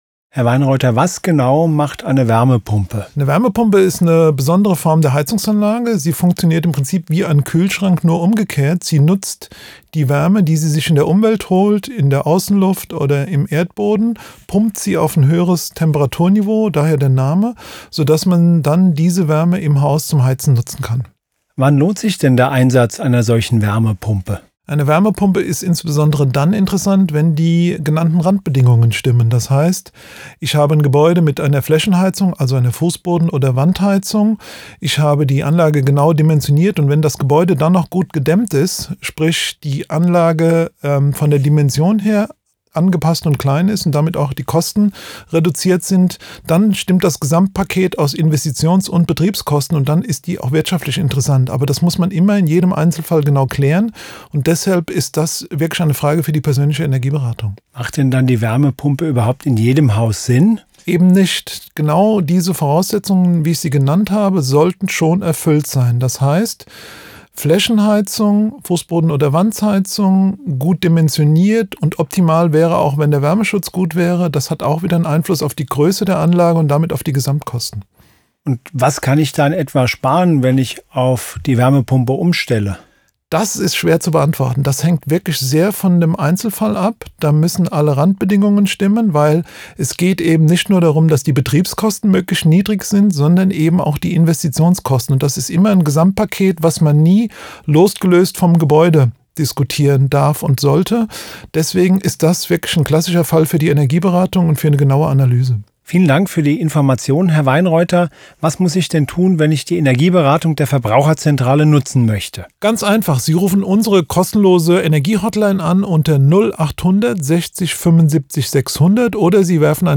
Interview zu wichtigen Energiethemen von A wie Atmende Wände über K wie Kellerdämmung bis W wie Wärmepumpe.